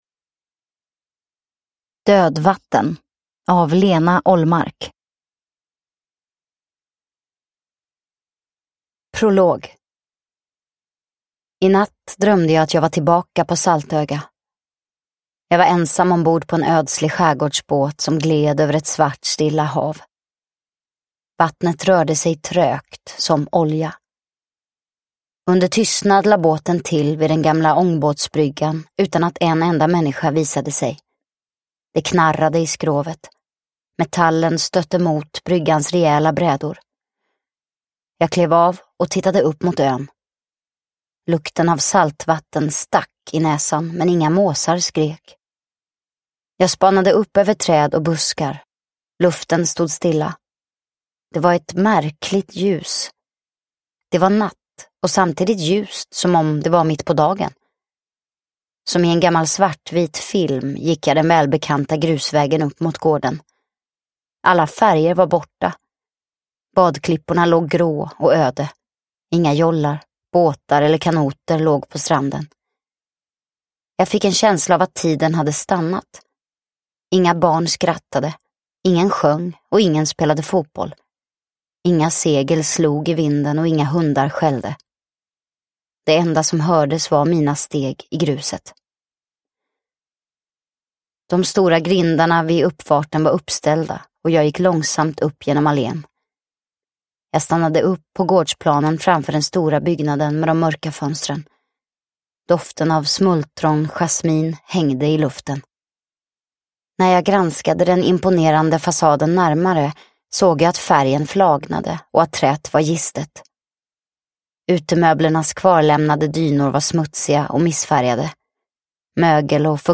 Dödvatten – Ljudbok – Laddas ner